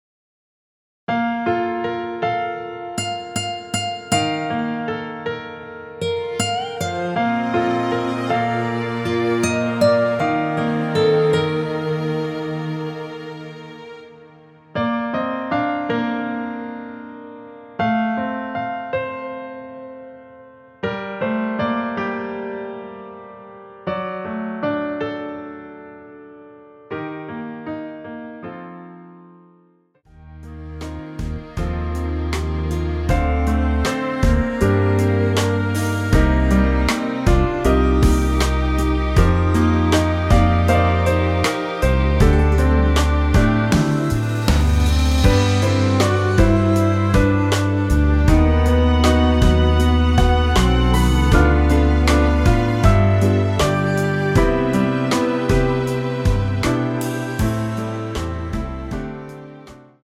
원키에서(-10)내린 MR입니다.
Bb
앞부분30초, 뒷부분30초씩 편집해서 올려 드리고 있습니다.
중간에 음이 끈어지고 다시 나오는 이유는